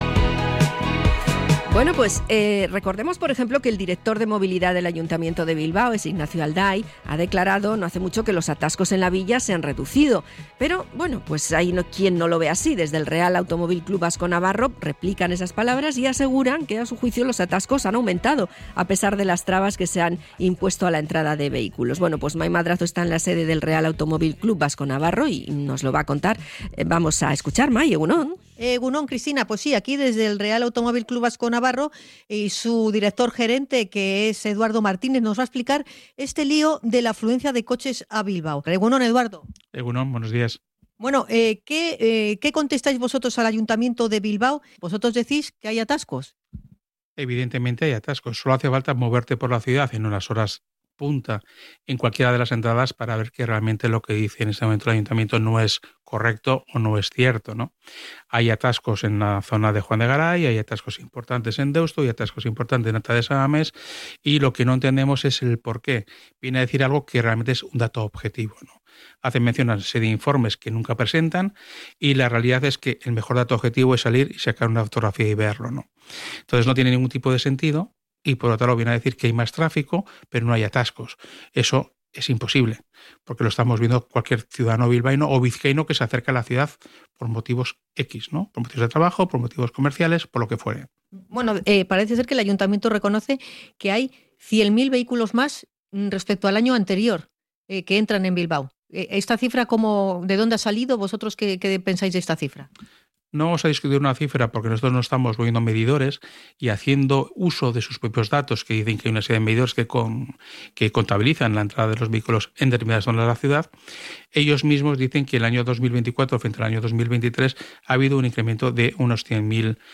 Podcast Bilbao